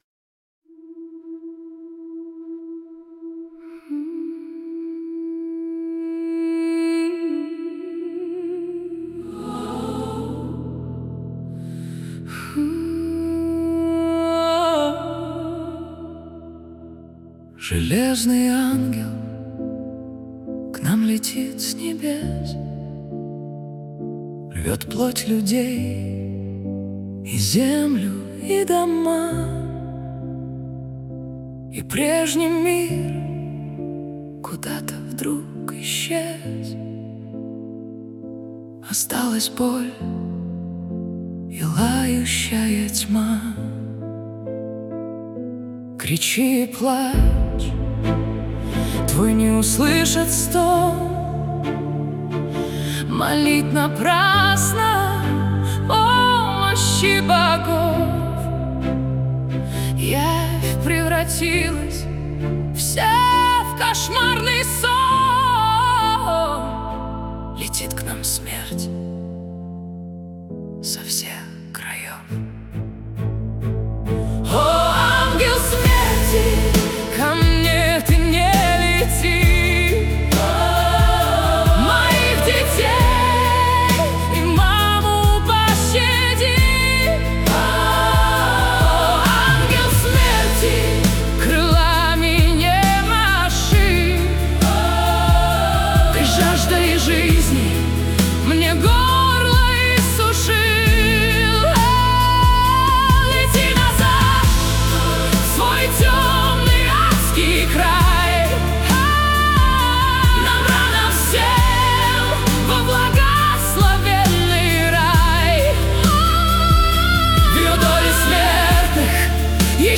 mp3,5330k] AI Generated